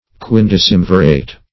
Search Result for " quindecemvirate" : The Collaborative International Dictionary of English v.0.48: Quindecemvirate \Quin`de*cem"vi*rate\, n. [L. quindecimviratus.]
quindecemvirate.mp3